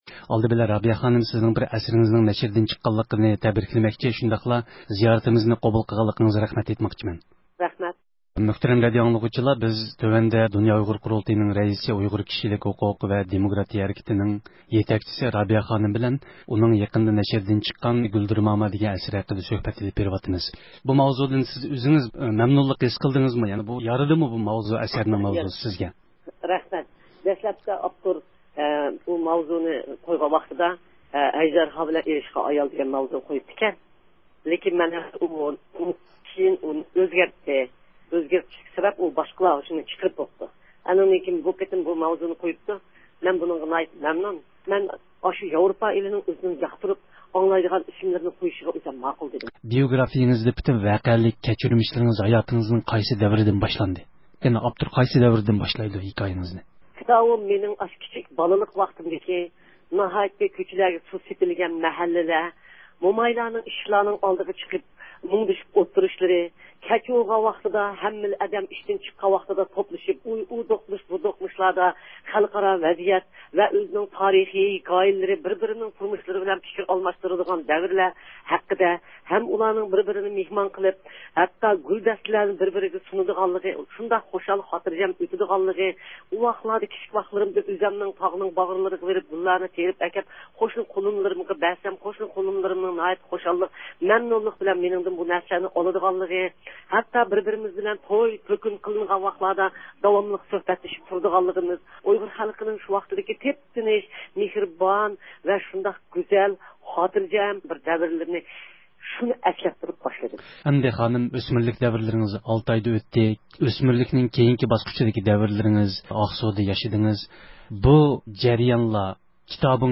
بۈگۈن رابىيە قادىر خانىم » گۈلدۈرماما» توغرىسىدا مۇخبىرىمىزغا سۆھبەت ئېلان قىلدى.